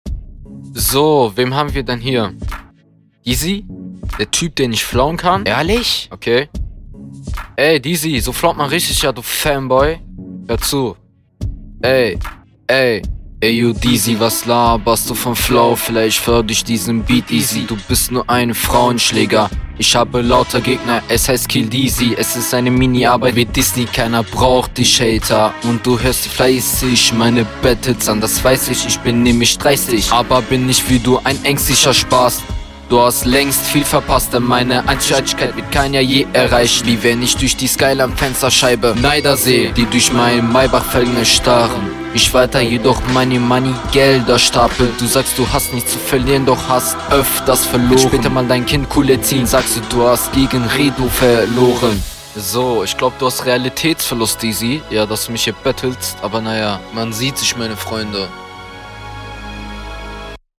Flow: Leider hat mich dein Flow nicht ganz angesprochen und du rappst zuviele Silben, wodurch …